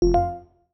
Organ double Hit.wav